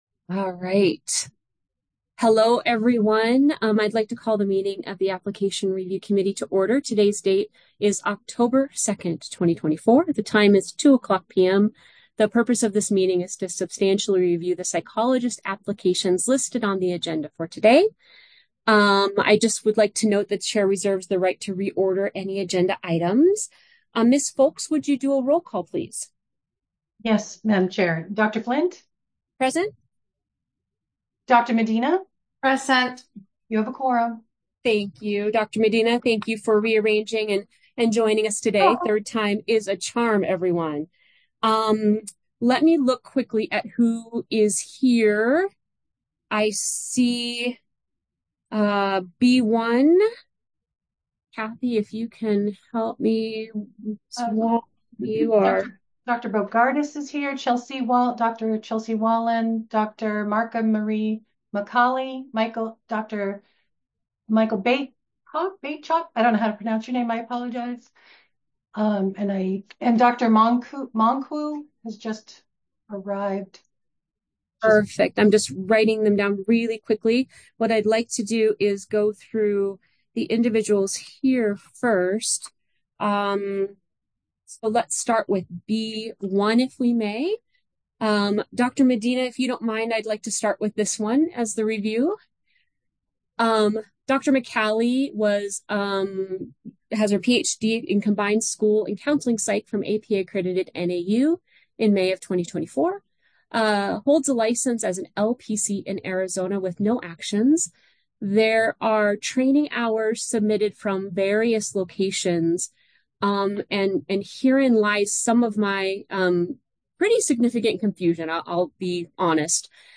Application Review Committee Meeting | Board of Psychologist Examiners
Members will participate via Zoom.